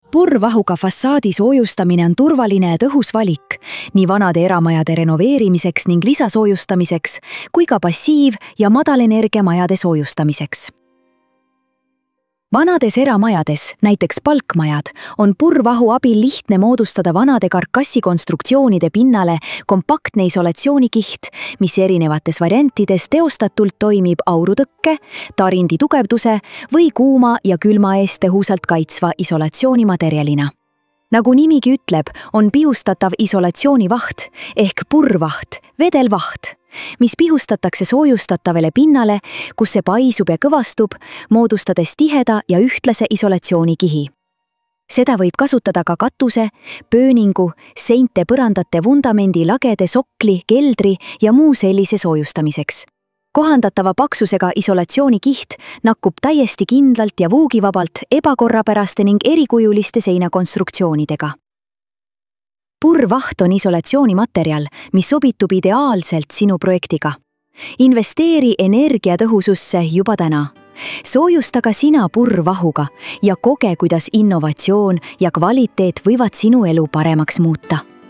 fassaadi-soojustamine-text-to-speech.mp3